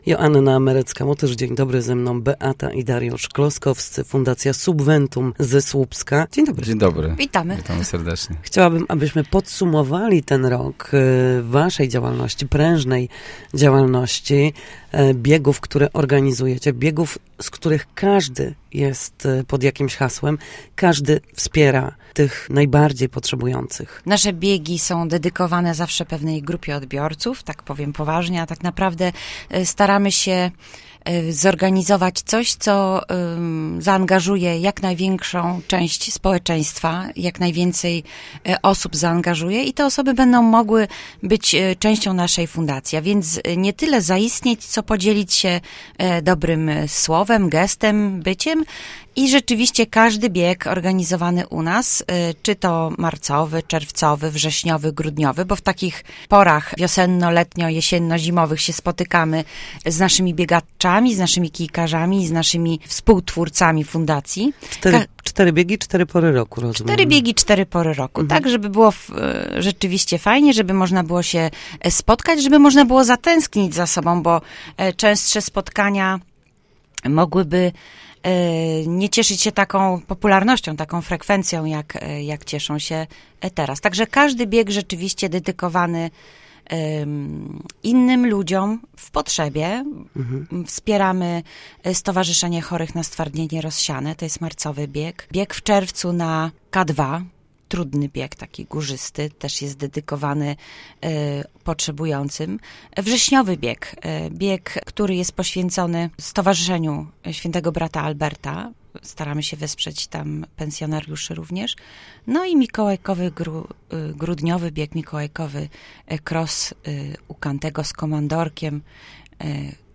Na naszej antenie podsumowali miniony rok fundacji, którą z powodzeniem od kilku lat prowadzą.